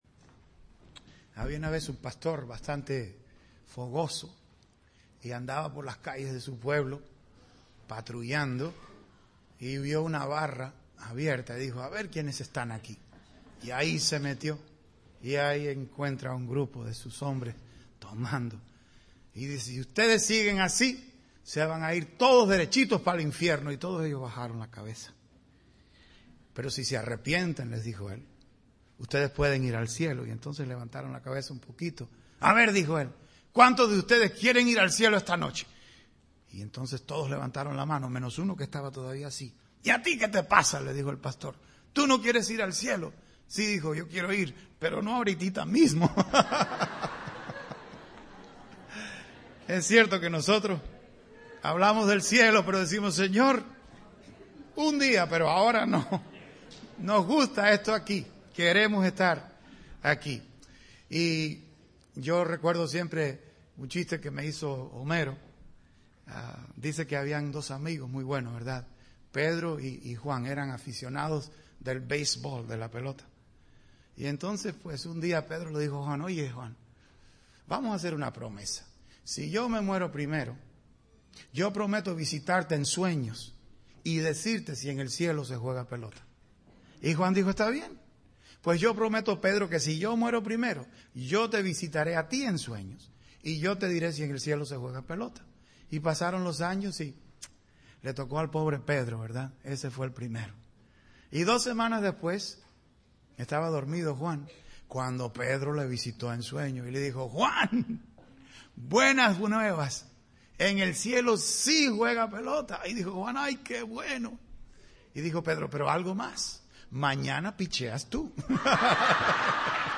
Servicio Dominical